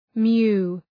Shkrimi fonetik {mju:}